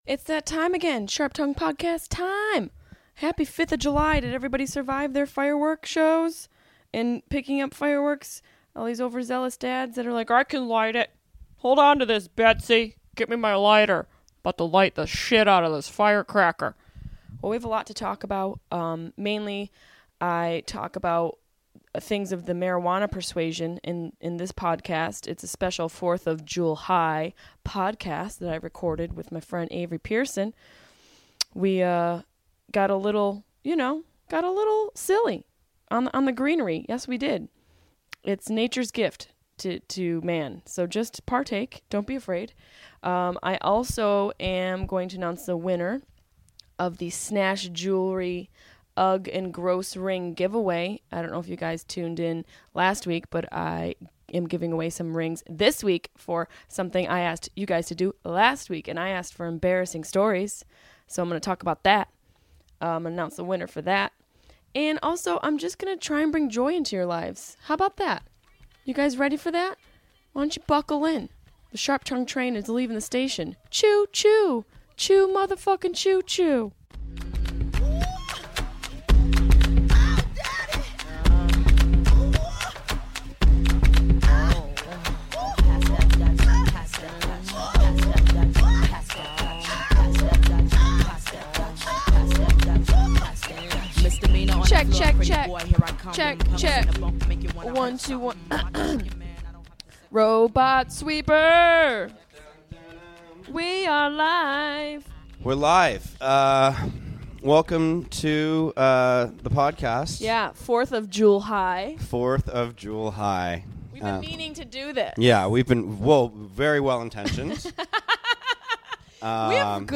I get high and talk complete nonsense! Warning: LOTS of singing.